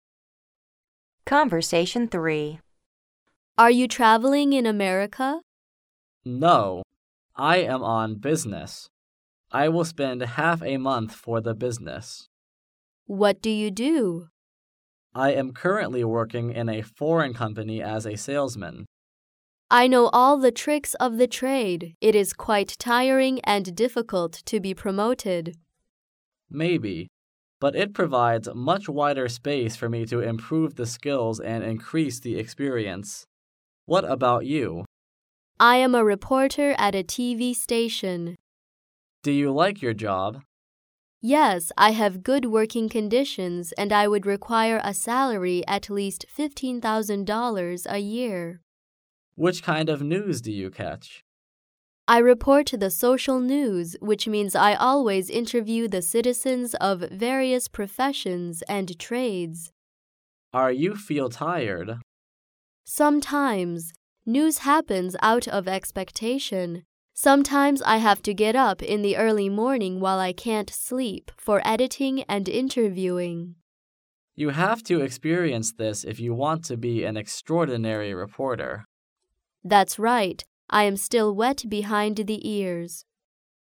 Conversation 3